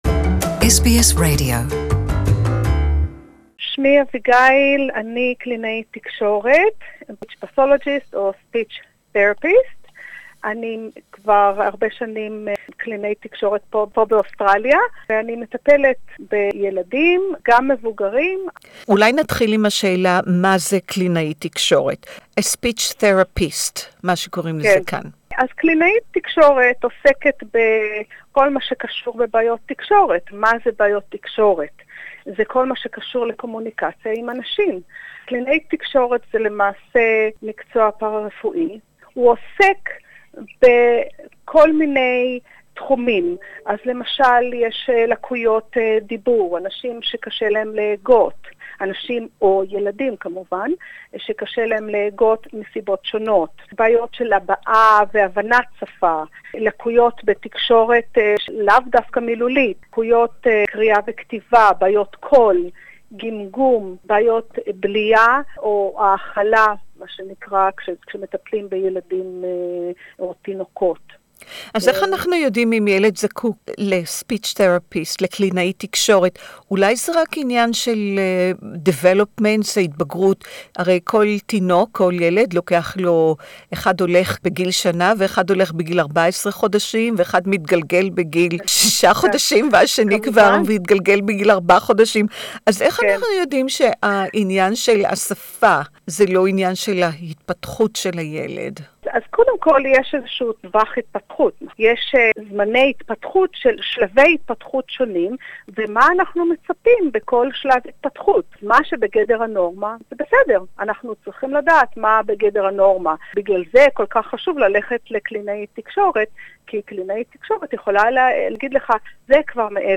Interview in Hebrew